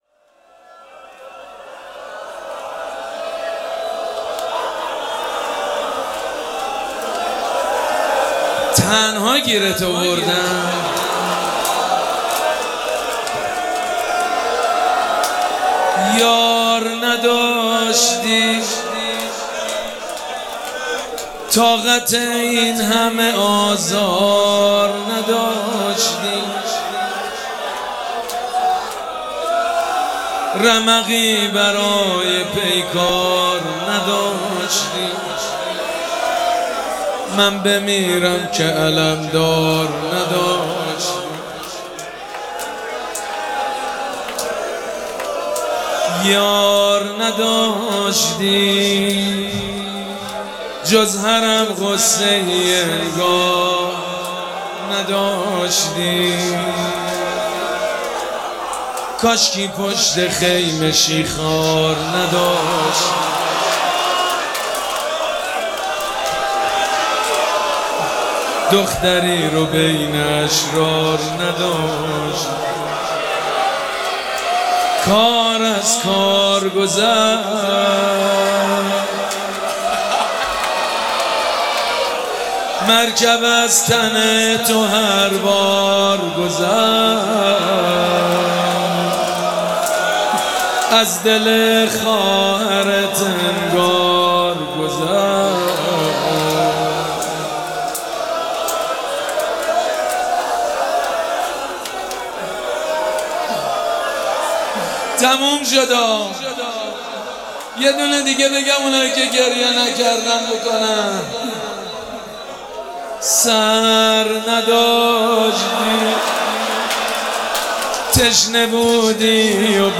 مراسم عزاداری شب دهم محرم الحرام ۱۴۴۷
روضه